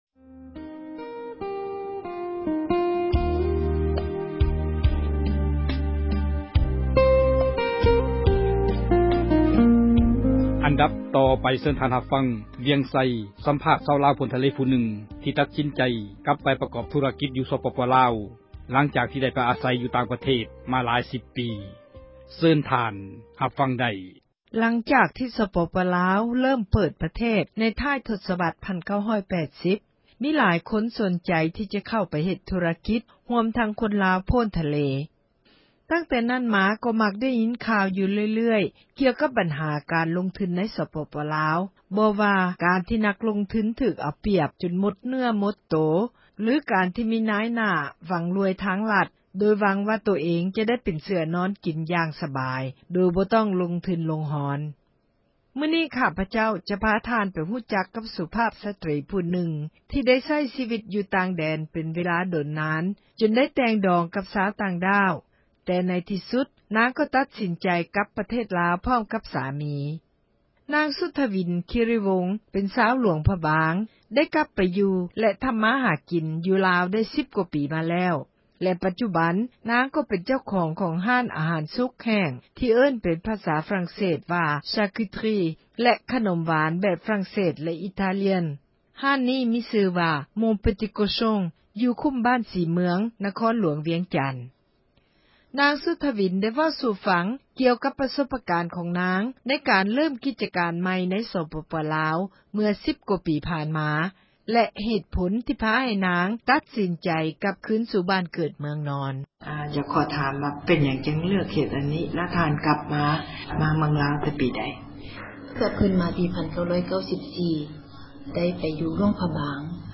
ສຳພາດລາວ ໂພ້ນທະເລຜູ້ນຶ່ງ